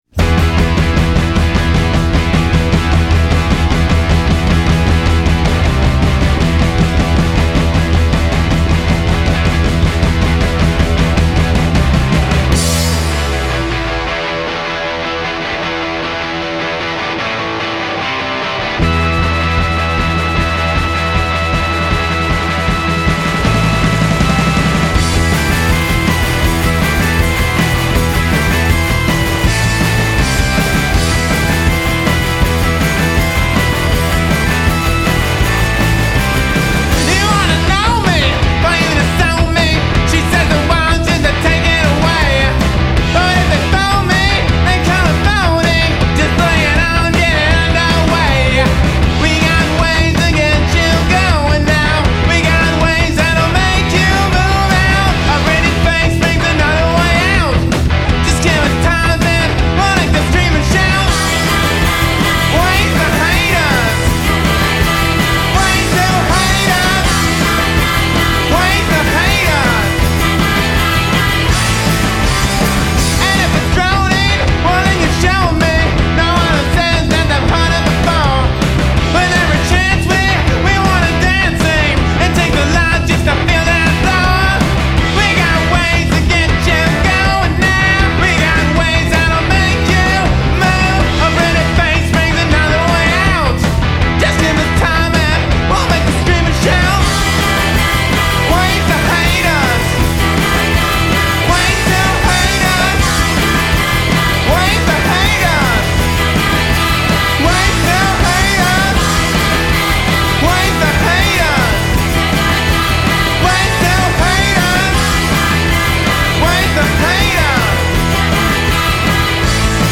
Guitar and Vocals
Bass
Drums
Keyboards